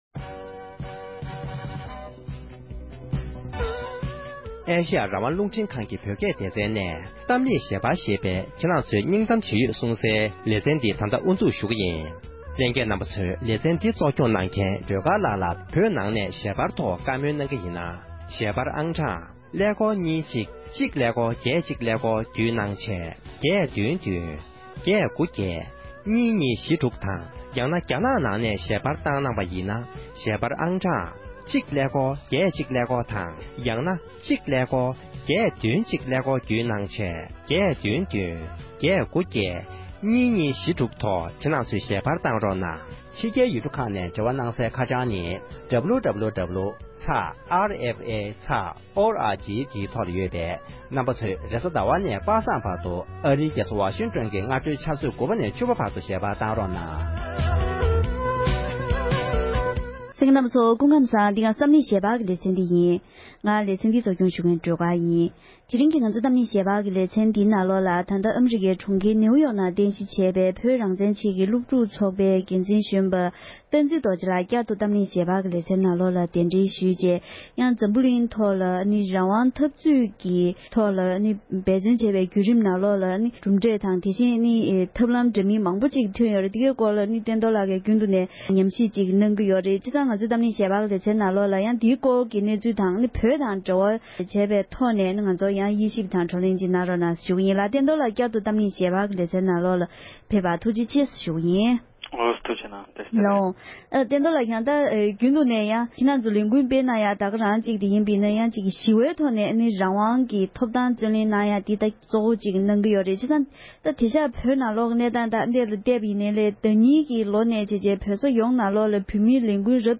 བོད་ནང་སྤེལ་བཞིན་པའི་རང་དབང་འཐབ་རྩོད་ཀྱི་ལས་འགུལ་དང་འབྲེལ་བའི་སྐོར་བགྲོ་གླེང༌།